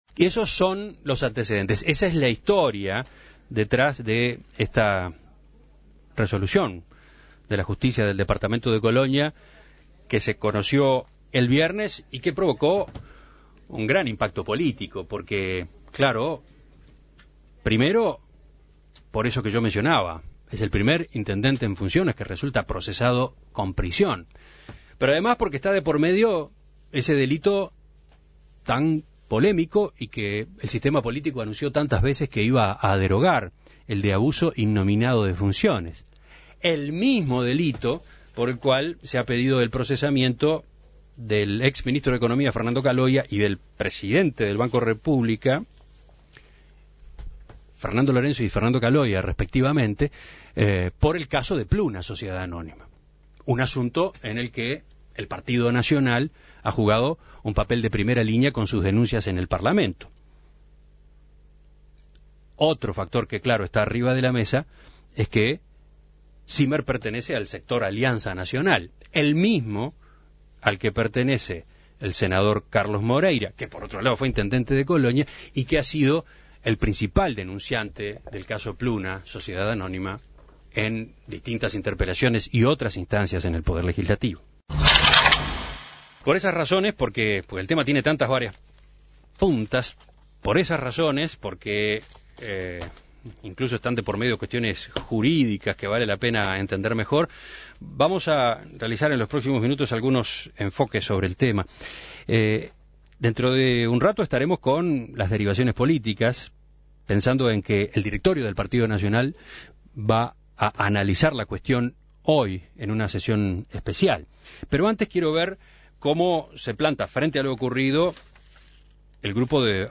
En diálogo con En Perspectiva